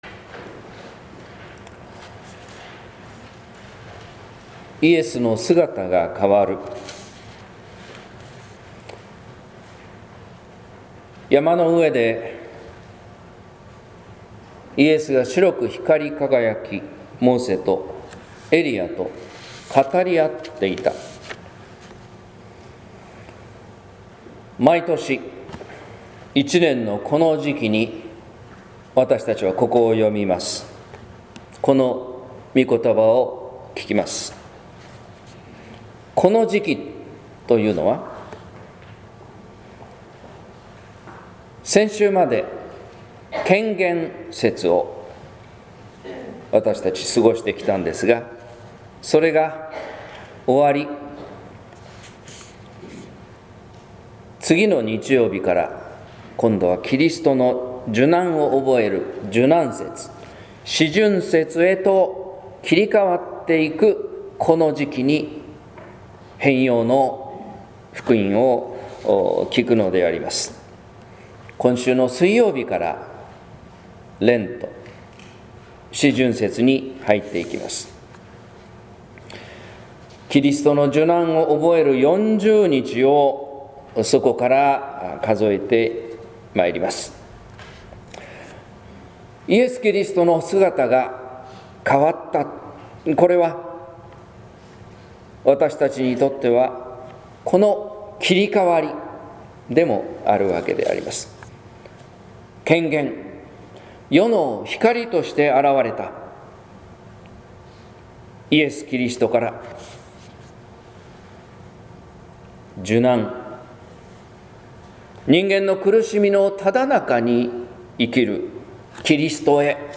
説教「栄光から十字架へ」（音声版） | 日本福音ルーテル市ヶ谷教会